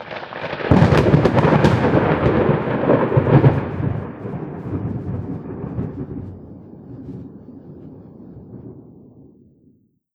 tenkoku_thunder_close01.wav